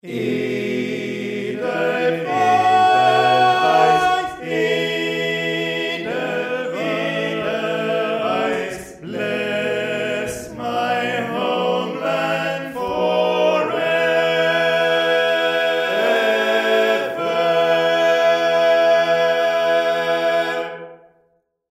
Key written in: E Major
Type: Barbershop